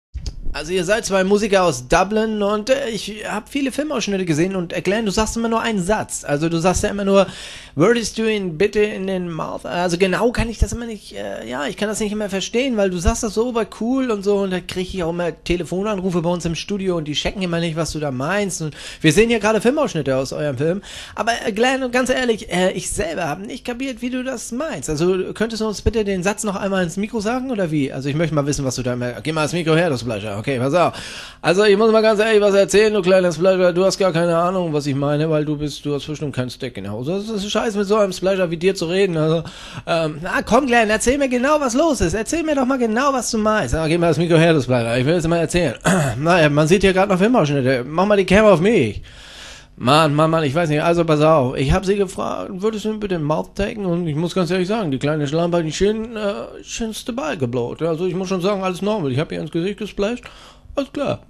In Mouth Taken III - Das Interview (128kbit_AAC).m4a